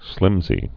(slĭmzē) also slimp·sy (slĭmpsē)